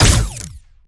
GUNAuto_Plasmid Machinegun B Single_03_SFRMS_SCIWPNS.wav